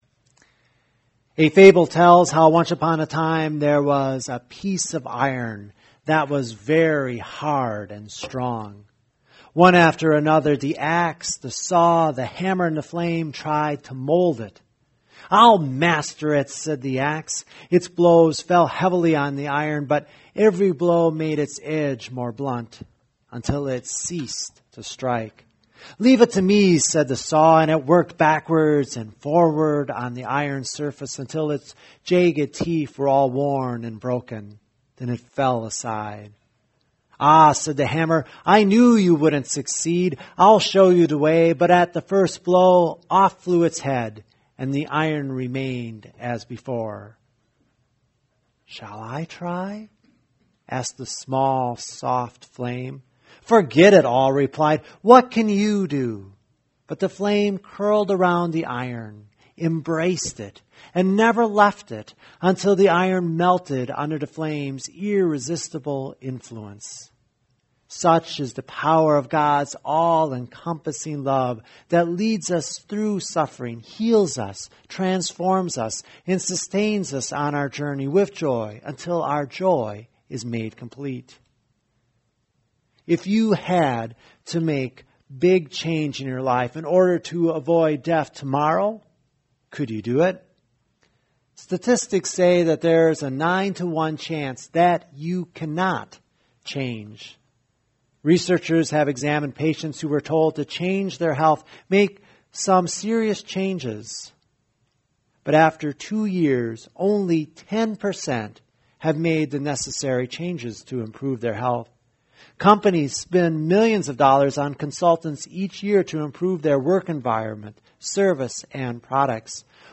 Sermon Archive – North Church